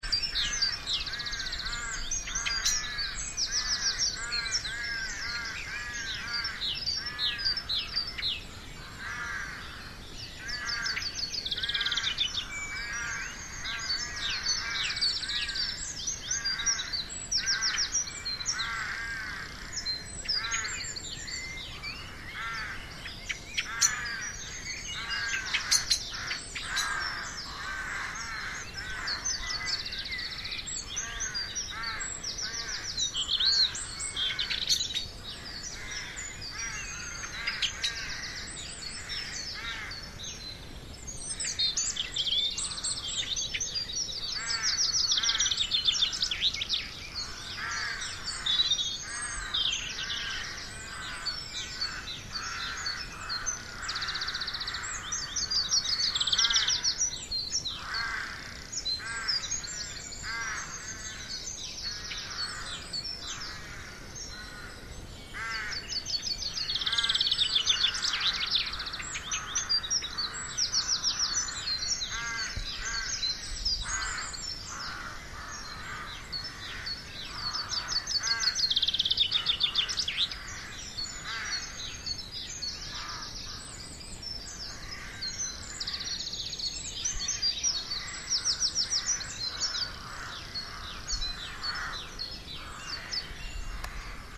The birdsong was amazing. Two chilly mornings I stumbled out of my dreams at 5.40am on to the frosted grass of the garden to record the dawn chorus:
Chaffinches, blackbirds, robins, a pheasant, rooks – we were beneath a rookery.
Dawn-chorus-5-loud.mp3